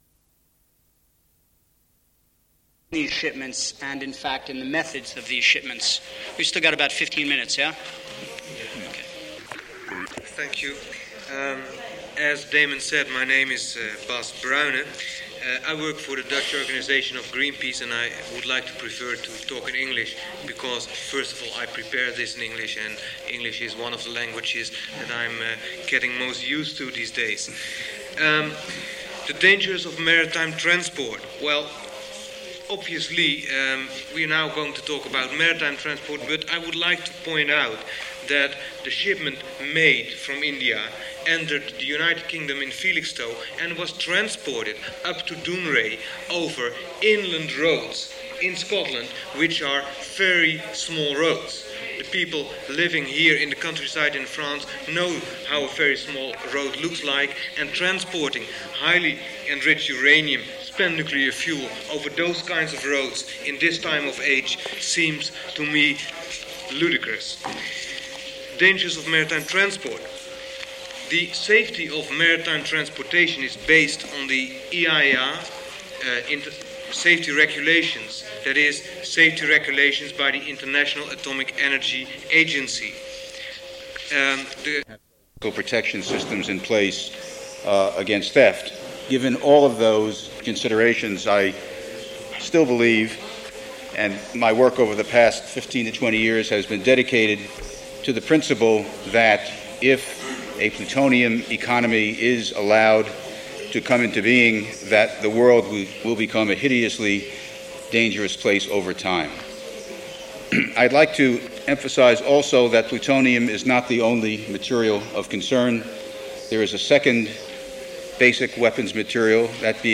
Présentation publique sur des questions liées au nucléaire (radioactivité, armes nucléaires...) Une personne de Greenpeace aux Pays-Bas discute des dangers des transports maritimes de déchets nucléaires. Puis la question de l'armement et du plutonium est abordée.